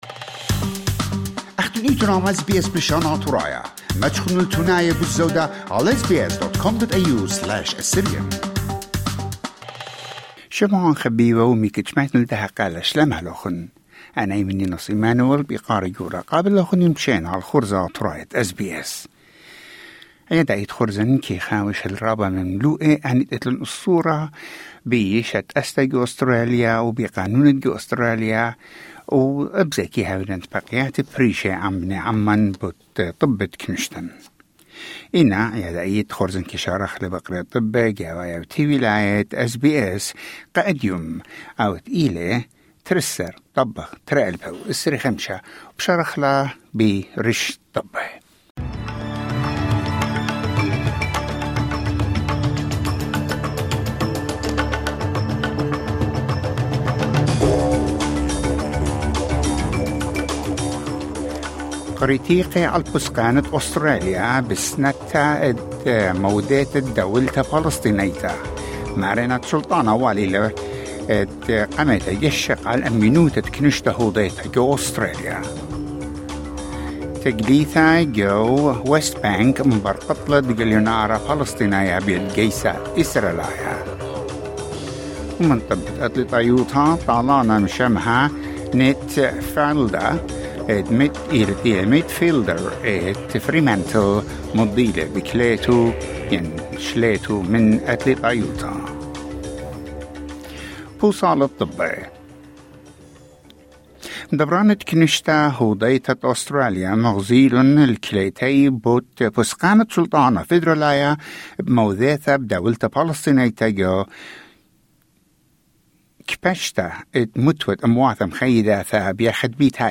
News Bulletin: 26 August 2025